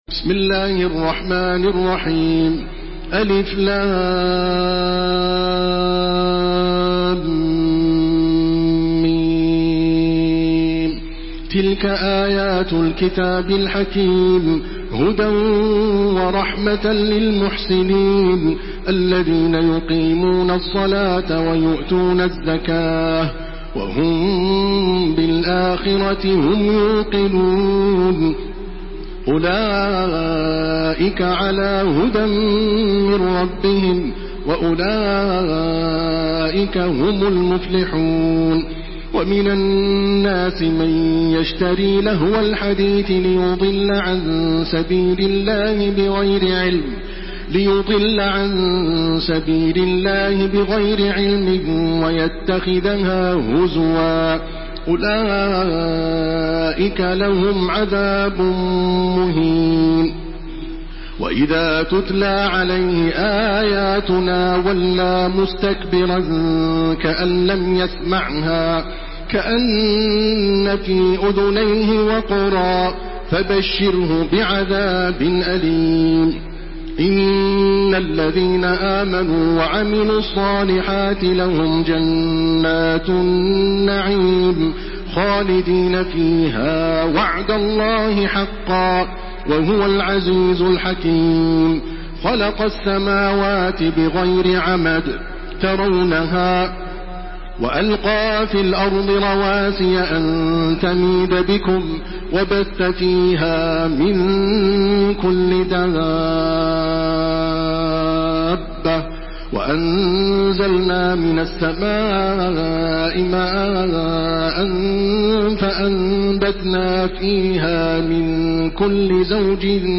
Surah Lokman MP3 by Makkah Taraweeh 1429 in Hafs An Asim narration.